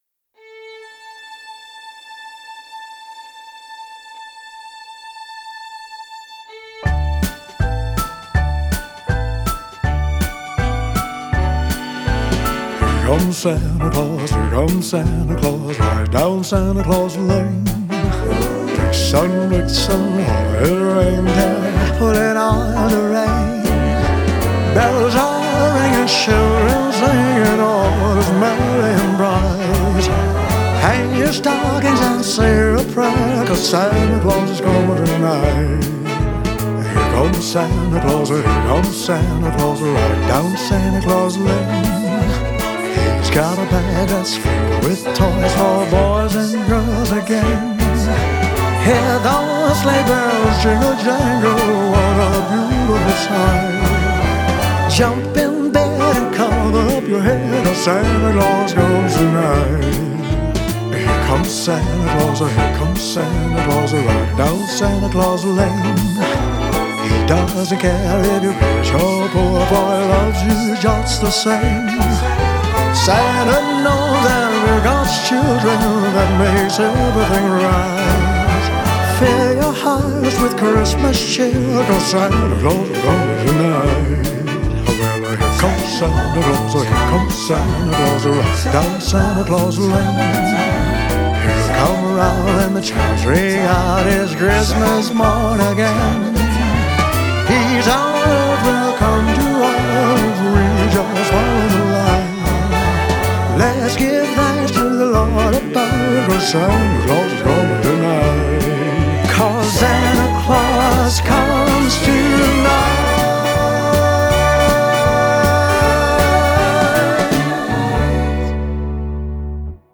Guide Vocal